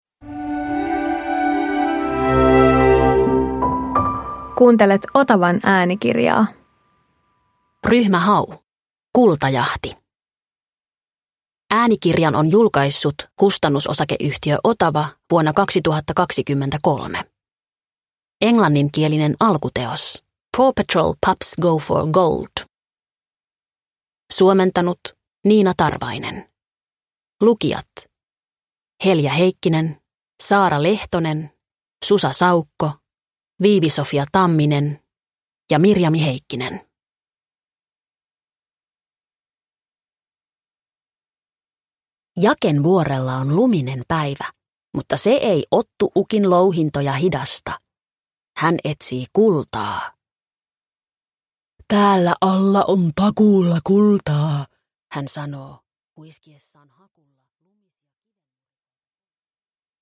Ryhmä Hau - Kultajahti – Ljudbok – Laddas ner